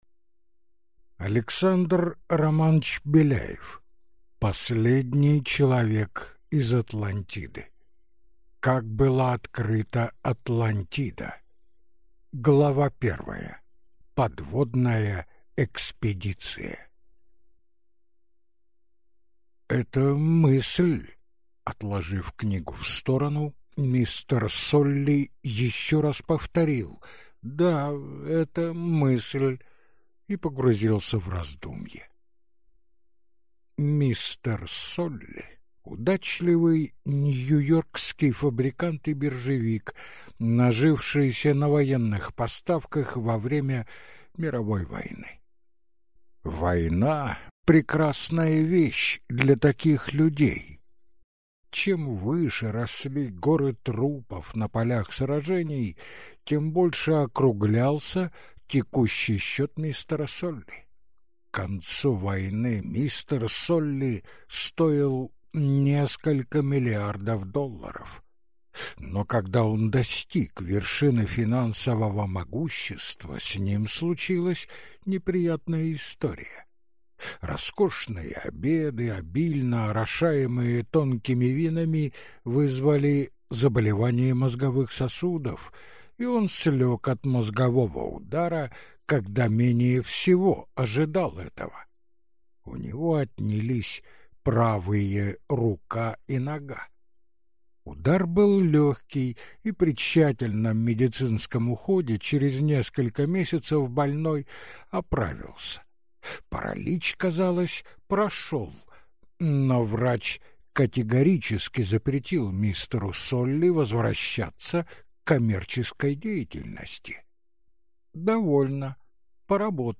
Аудиокнига Последний человек из Атлантиды | Библиотека аудиокниг